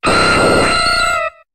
Cri de Ptéra dans Pokémon HOME.